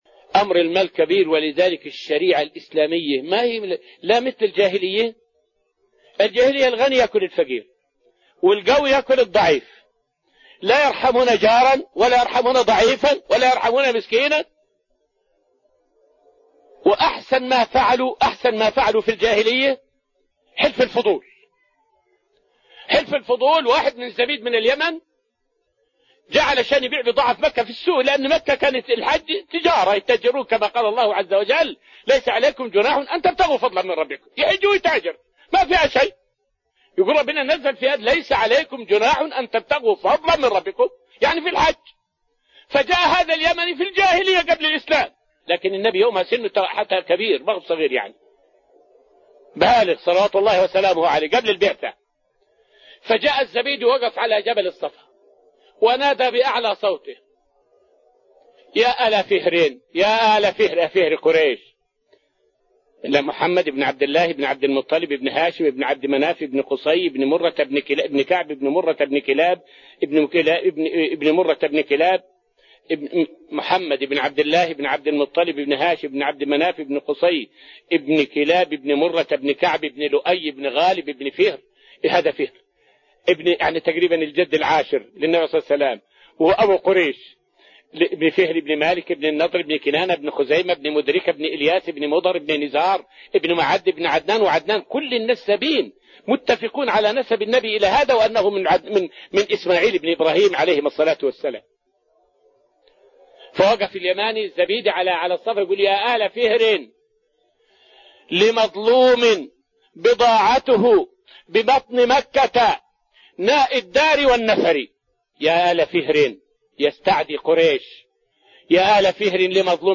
فائدة من الدرس الثاني من دروس تفسير سورة الأنفال والتي ألقيت في رحاب المسجد النبوي حول حلف الفضول.